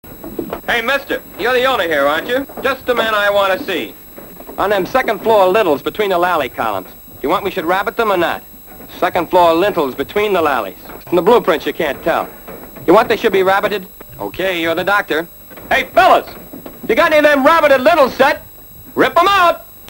Hörprobe mit Lex' eigener Stimme